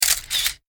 Photo_shutter.ogg